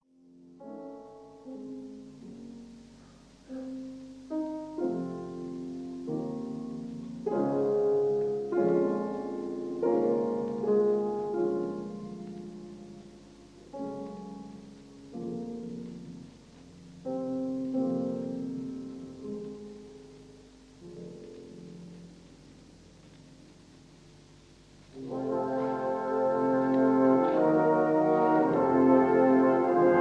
Recorded live at a 1955 season
given in the Royal Albert Hall, London